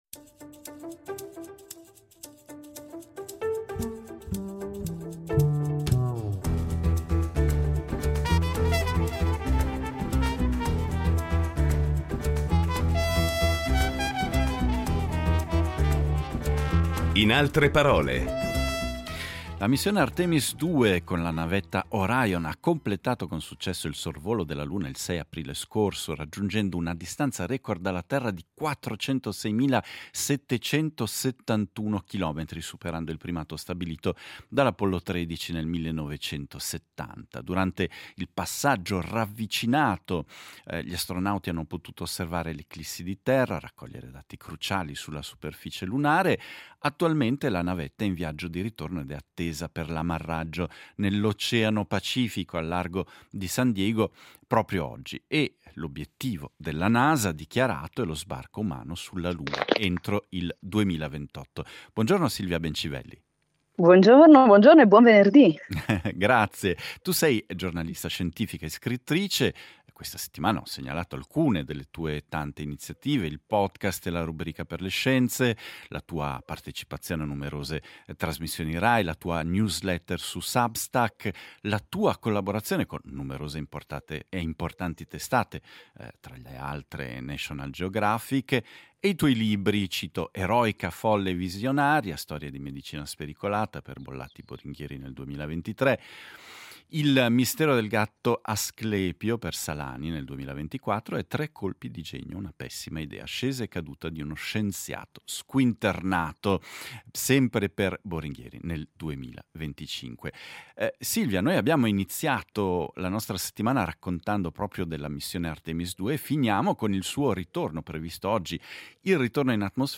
Incontro con la giornalista scientifica, scrittrice, autrice e conduttrice radiotelevisiva